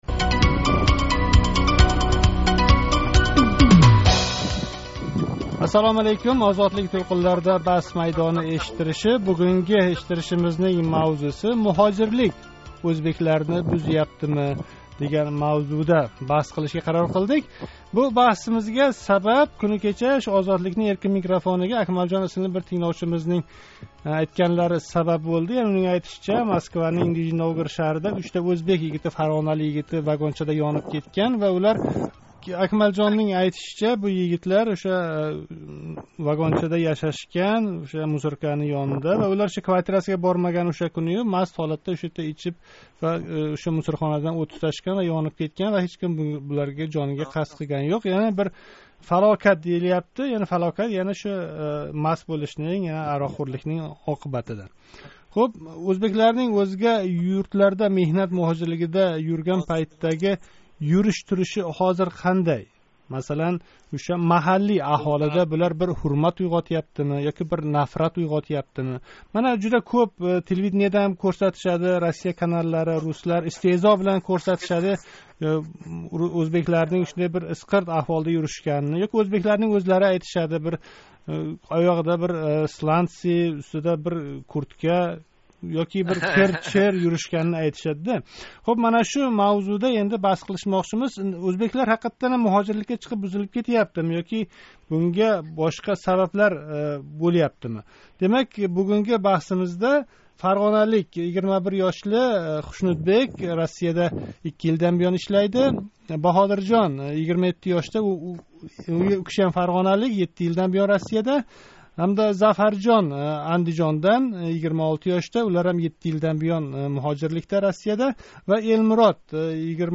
Озодликнинг навбатдаги Баҳс майдонида Ўзбекистондан ташқарида юрган меҳнат муҳожирларининг ўзини тутиши, кийиниши, озодалиги ҳақида баҳс юритилади. Баҳс майдонига муҳожиратдаги ўзбеклар ўз юриш-туриши билан ўзбеклигини кўрсатяпти ёки ўзбеклар ўзининг бугунги рафтори билан миллатни шарманда қиляпти, дегувчиларни таклиф этдик.